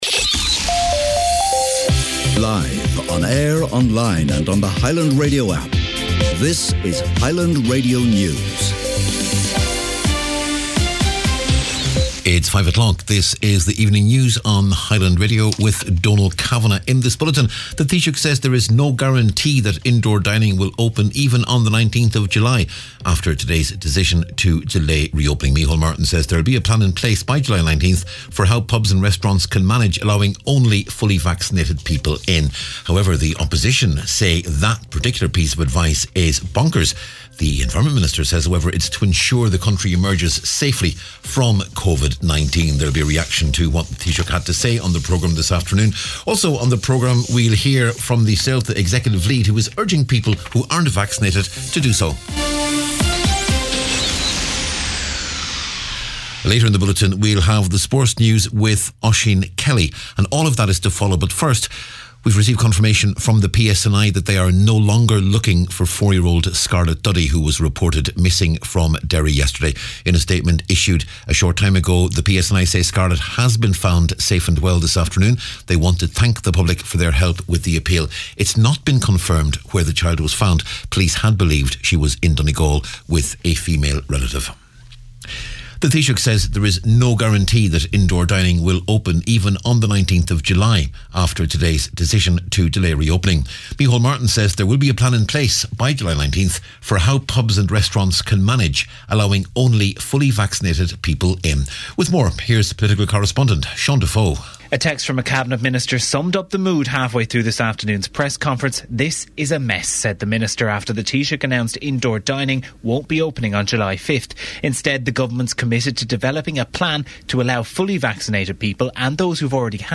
News, Sport and Obituaries on Tuesday June 29th